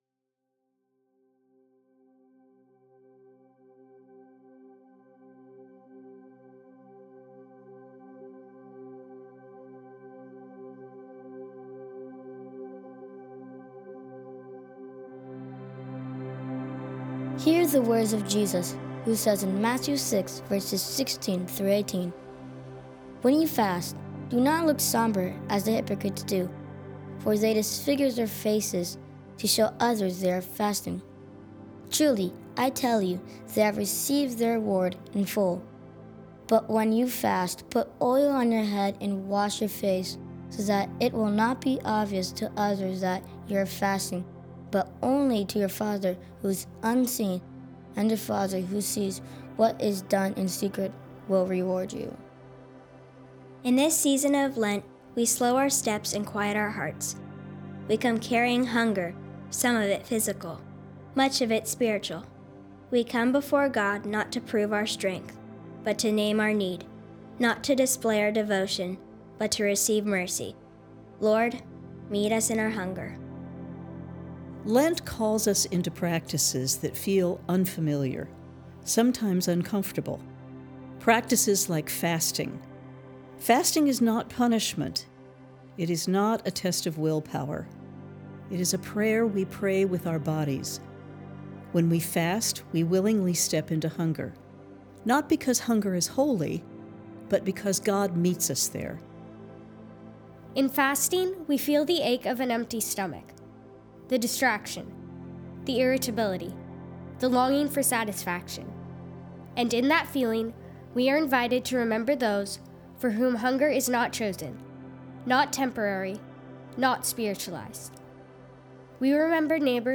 Lent Liturgy 2026 Vox With Music EDIT_V2.mp3